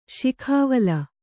♪ śikhāvala